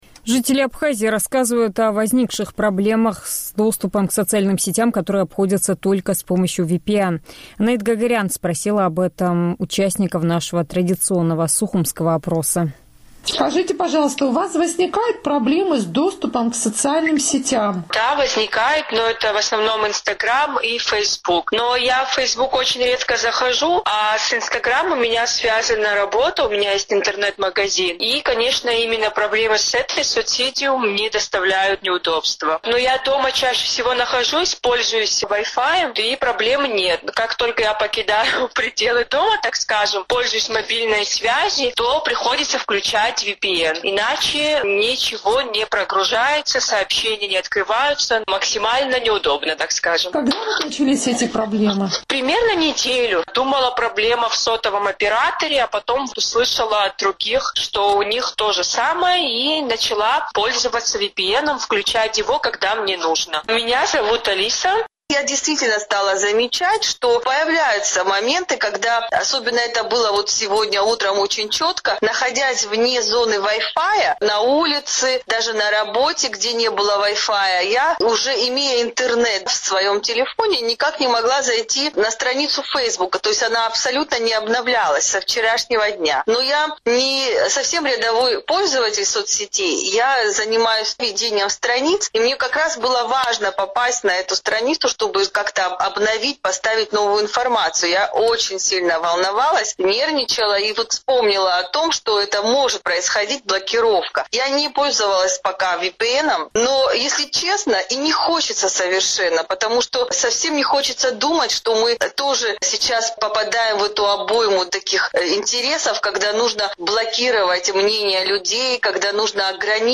Сухумский опрос – о проблемах с доступом к соцсетям